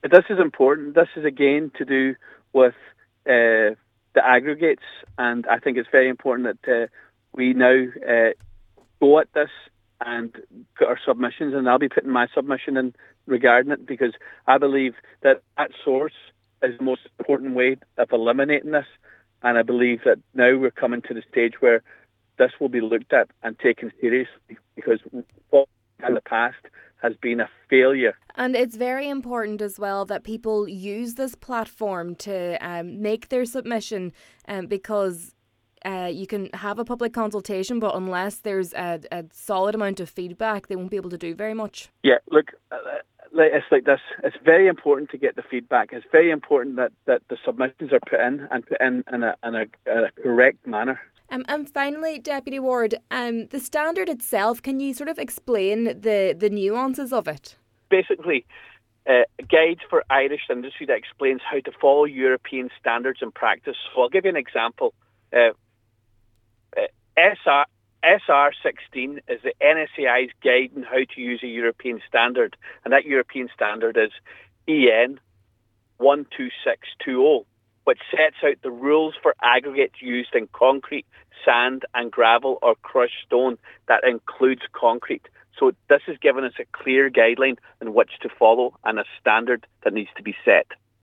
Deputy Charles Ward says the public now have to use their voice, but it’s important they take the time to properly assess the 36 page document: